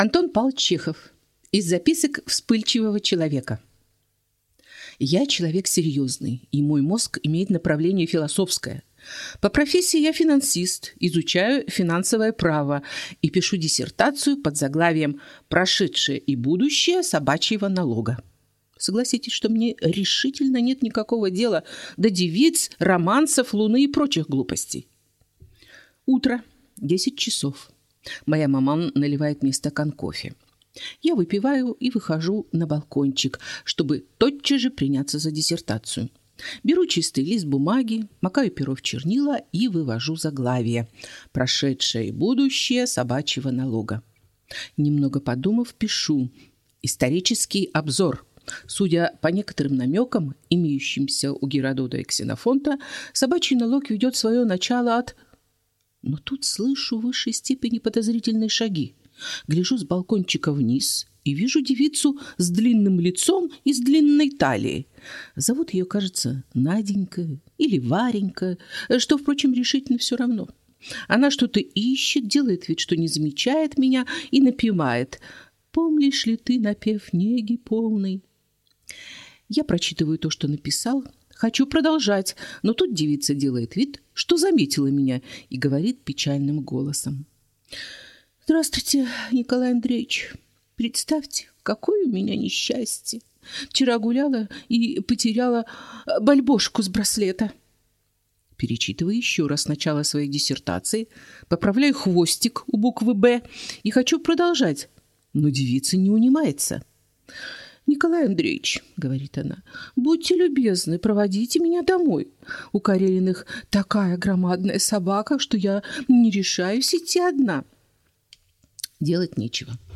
Аудиокнига Из записок вспыльчивого человека | Библиотека аудиокниг